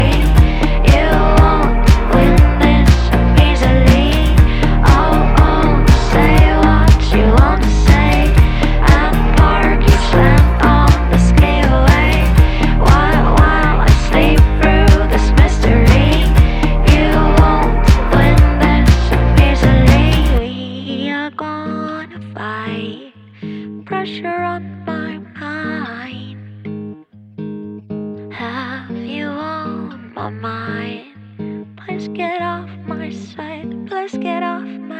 Жанр: Поп / Инди